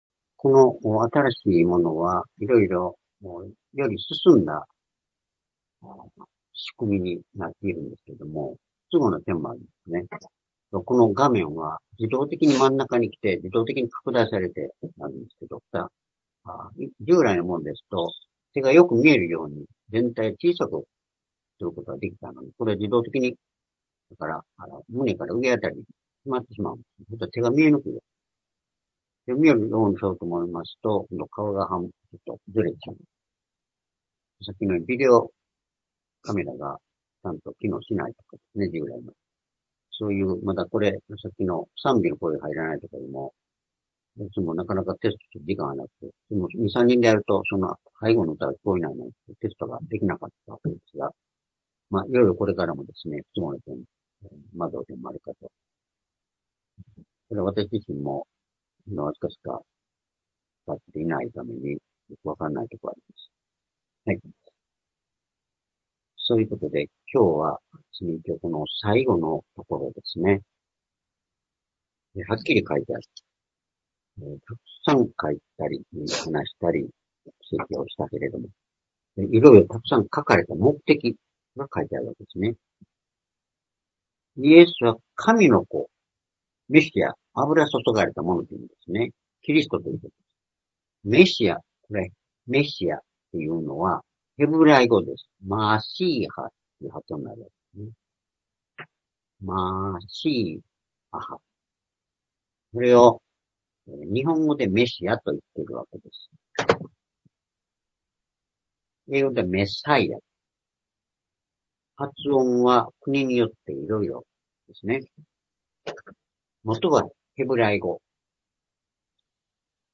主日礼拝日時 ２０２５年５月１８日（主日礼拝） 聖書講話箇所 「イエスを神の子と信じて永遠の命を受ける」 ヨハネ２０章３０節～３１節 ※視聴できない場合は をクリックしてください。